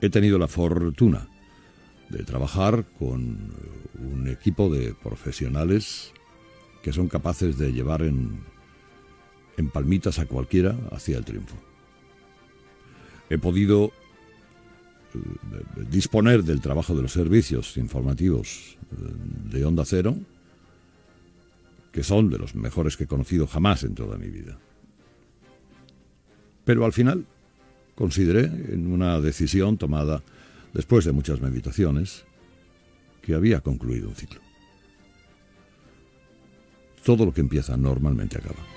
Paraules de comiat de Carlos Herrera de la cadena Onda Cero.
Info-entreteniment
FM